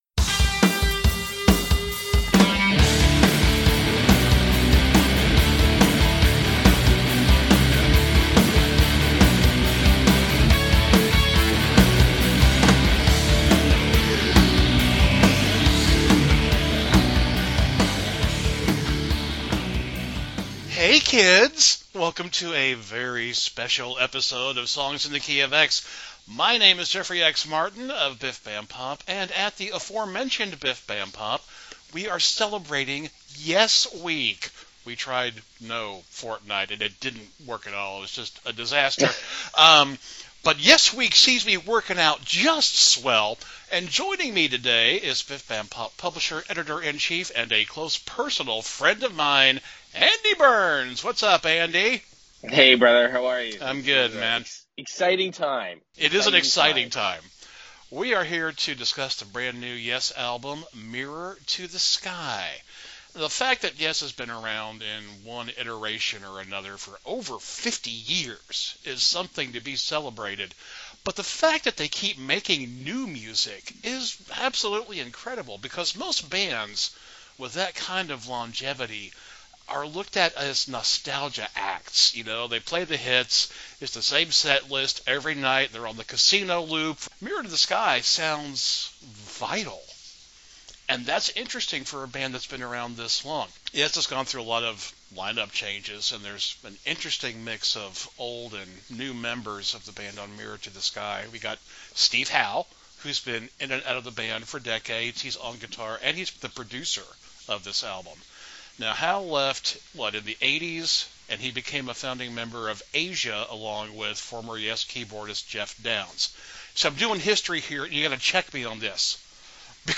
These two guys like Yes.